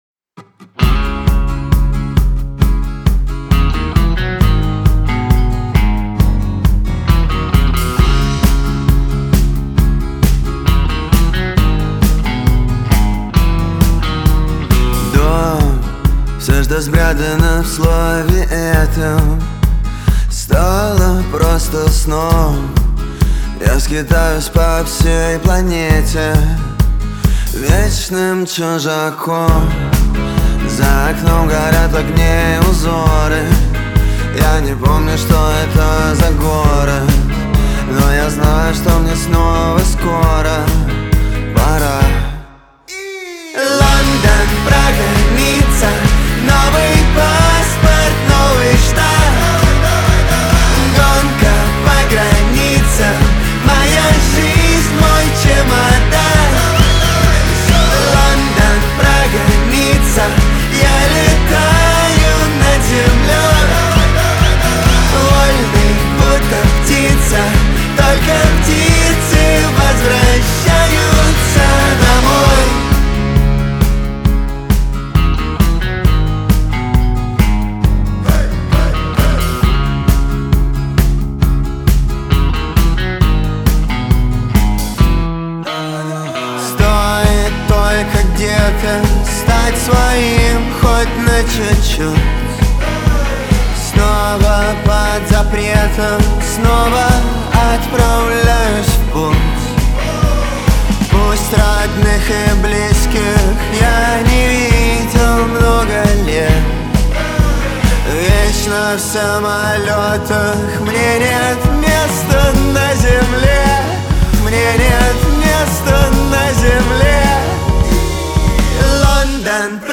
Жанр: rusrock
Rap, Hip-Hop